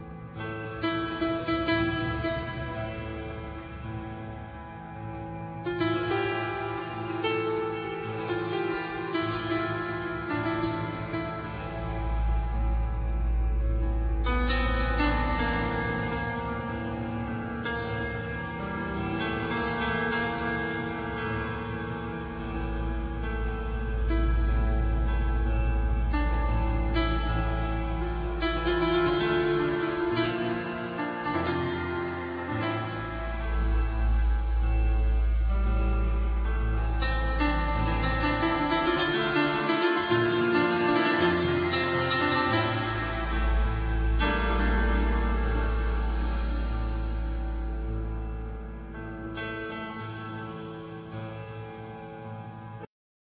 Piano,Synthesizer
Drums
Bass